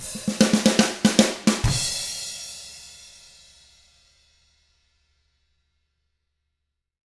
146ROCK E1-L.wav